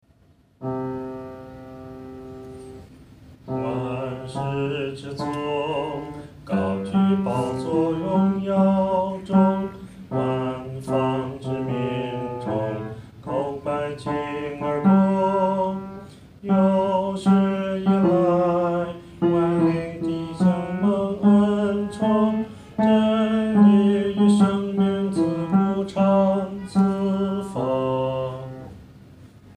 独唱（第四声）